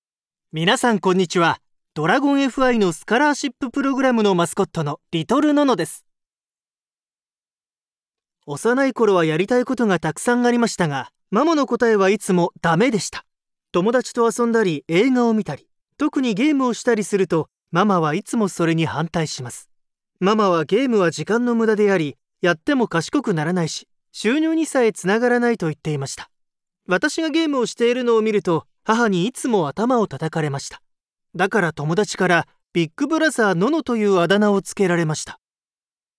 角色对白-沉稳自然